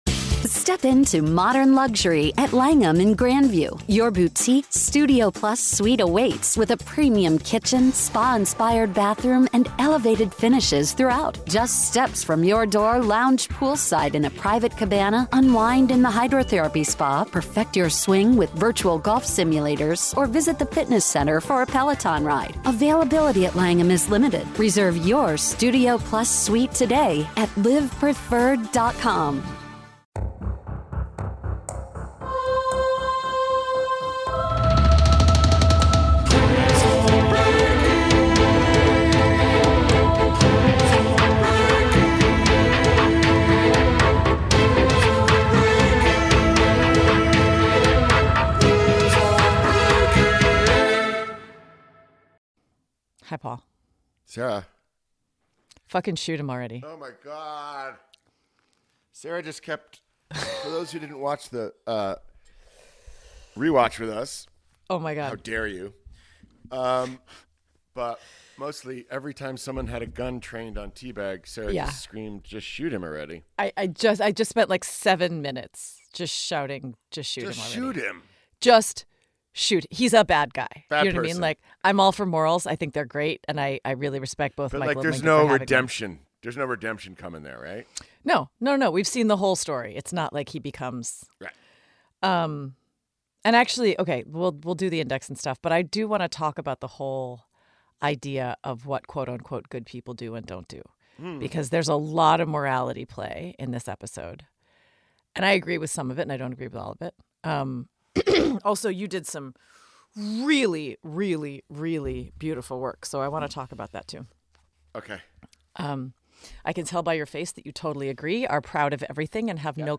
In this episode, hosts Sarah Wayne Callies and Paul Adelstein dive into Season 2, Episode 21 of 'Prison Break,' titled 'Fin del Camino,' which aired on March 26, 2007.